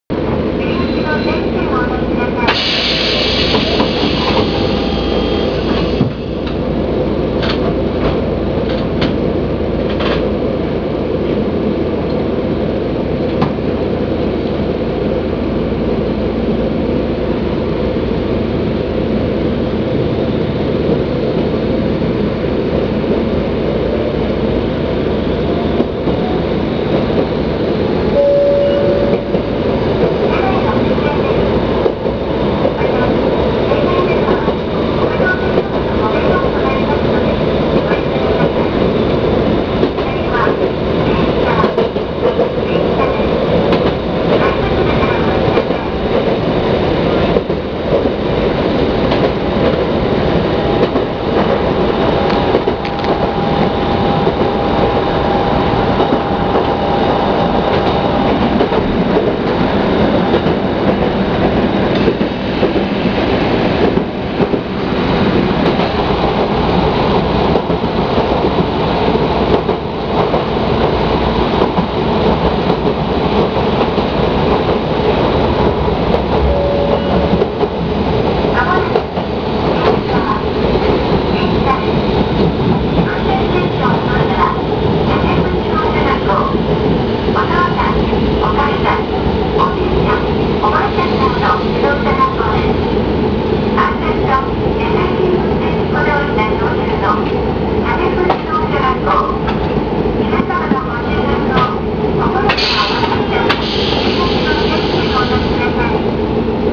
〜車両の音〜
880形走行音
【福武線】水落→神明（1分46秒：580KB）
ごく普通の抵抗制御です。路面電車の車両ではありますが、普通の鉄道線区間では結構な速度を出したりします。